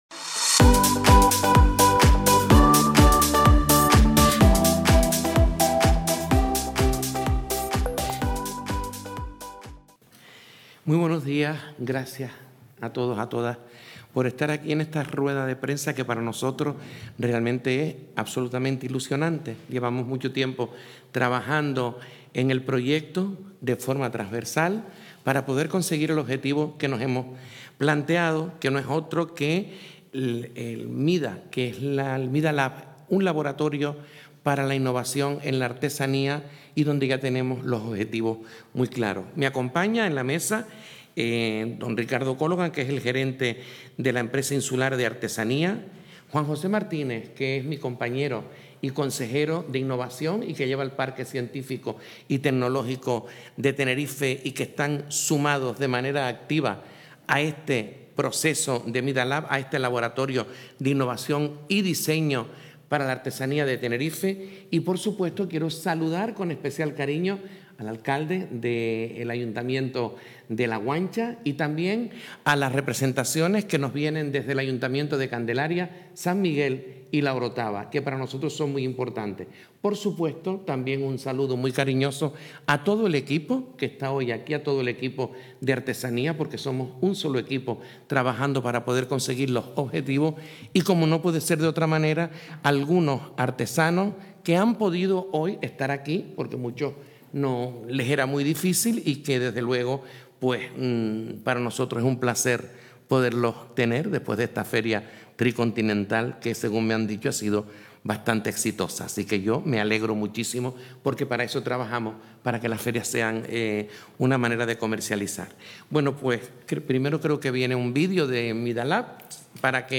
Recientemente emitido: El Salón Noble del Cabildo de Tenerife acogee este lunes, 18 de noviembre, a las 10:00 horas, la presentación del Laboratorio Creativo de Diseño y Artesanía (MidaLab 2024).